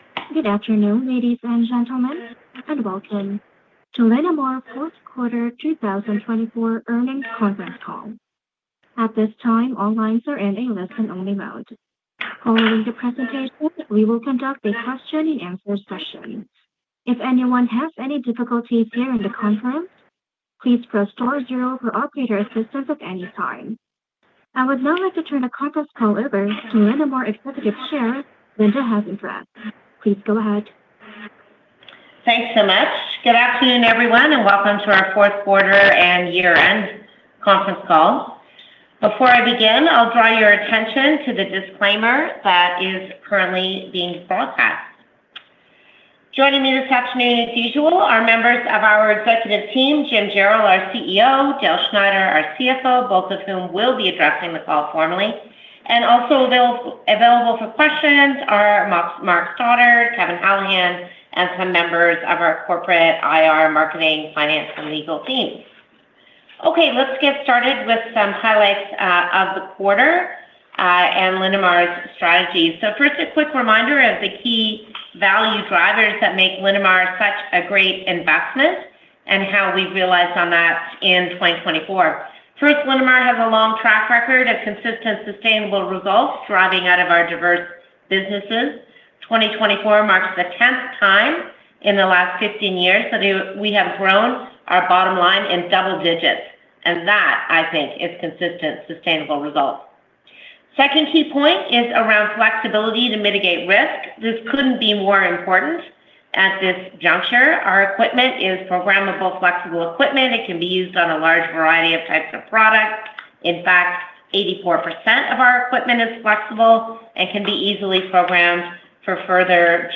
Linamar-Q4-2024-Earnings-Call.mp3